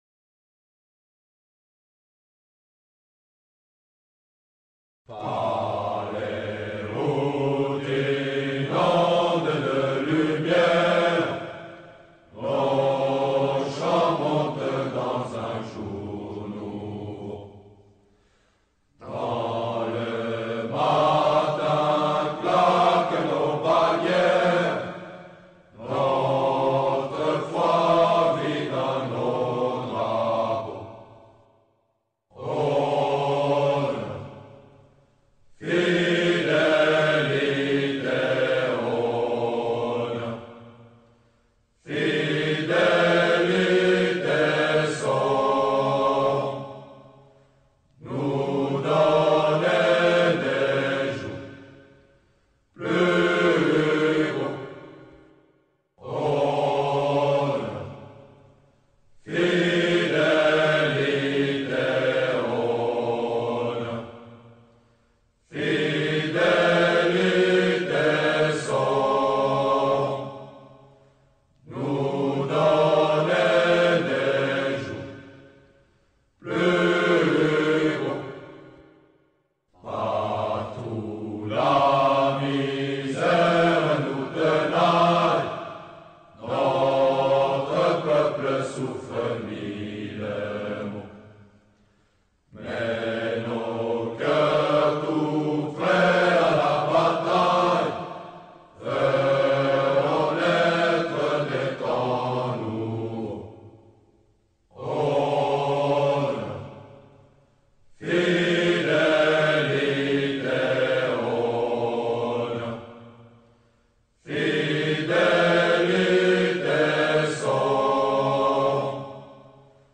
honneur-fidelite-chant-de-la-legion-etrangere-french-foreign-legion.mp3